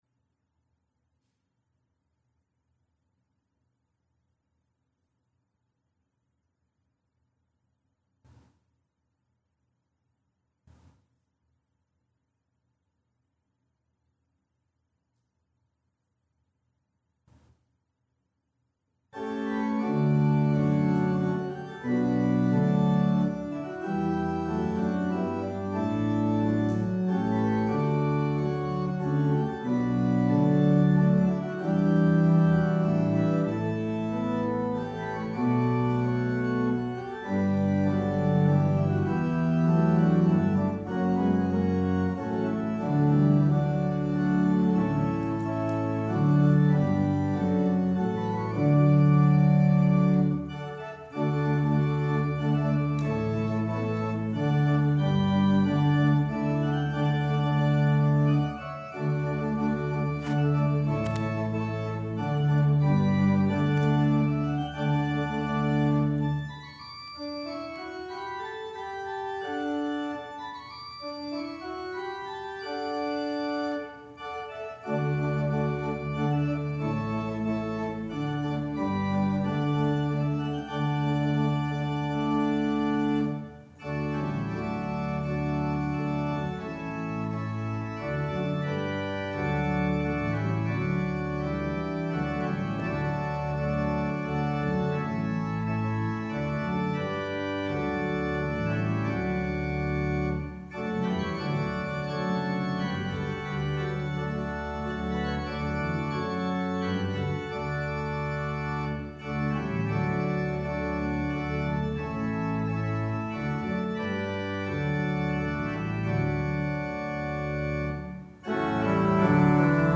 Sermon 5th Sunday after Epiphany